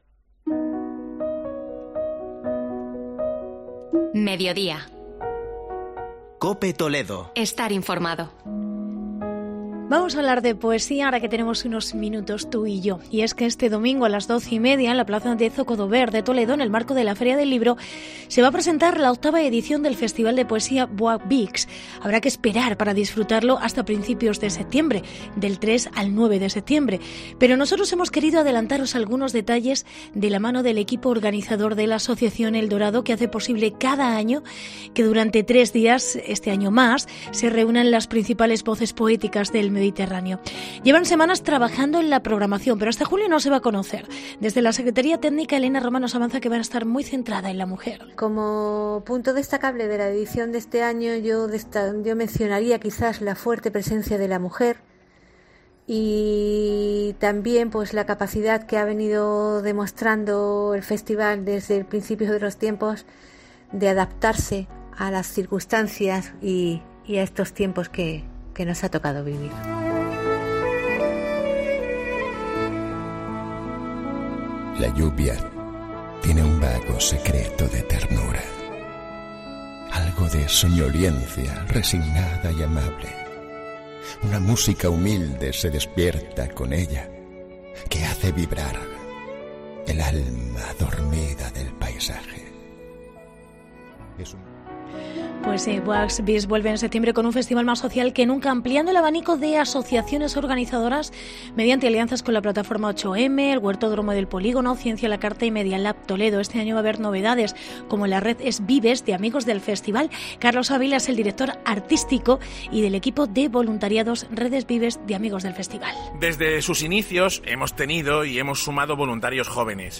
Reportaje Festival Voix